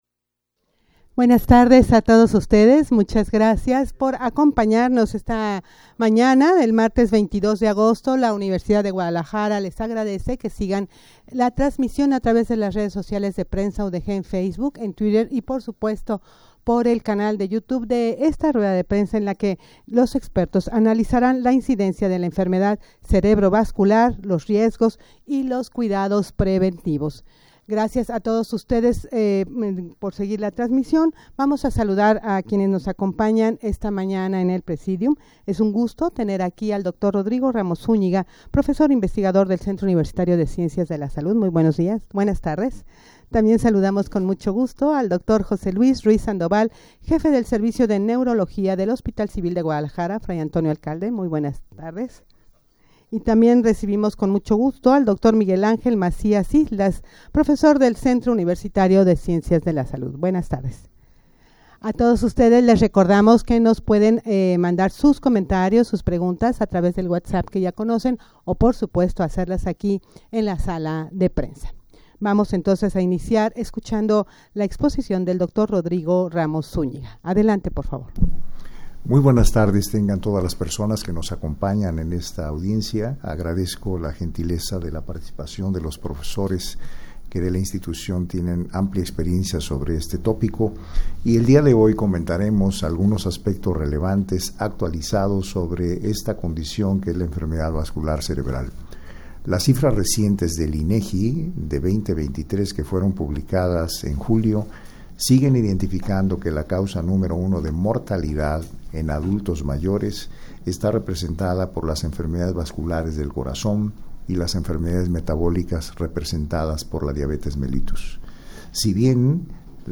Audio de la Rueda de Prensa
rueda-de-prensa-para-analizar-la-incidencia-de-la-enfermedad-cerebrovascular-riesgos-y-cuidados-preventivos.mp3